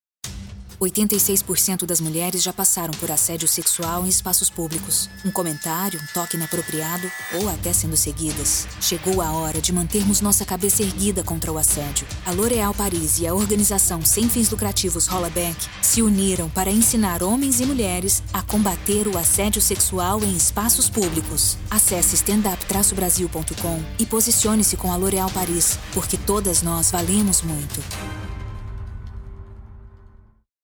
Natural, Reliable, Friendly, Soft, Corporate
Explainer